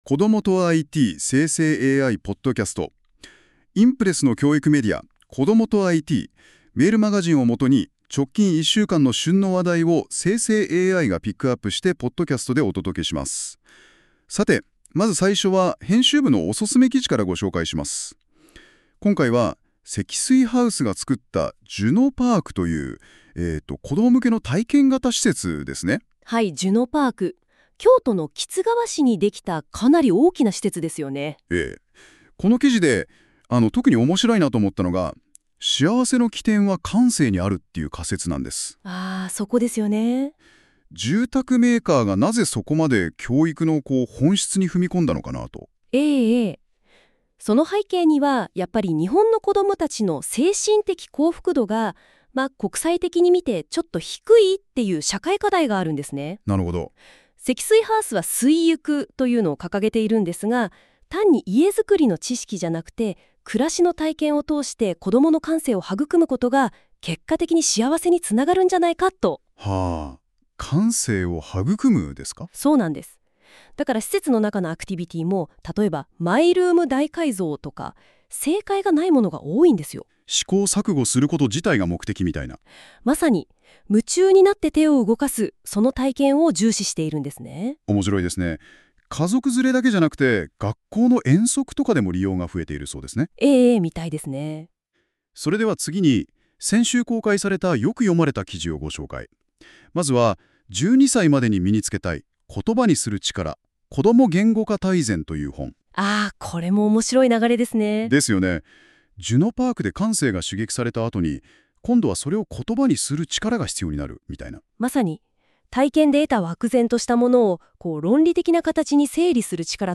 この記事は、『こどもとIT』メールマガジンを元に、先週１週間の旬の話題をNotebookLMでポッドキャストにしてお届けする、期間限定の実験企画です。 ※生成AIによる読み上げは、不自然なイントネーションや読みの誤りが発生します。 ※この音声は生成AIによって記事内容をもとに作成されています。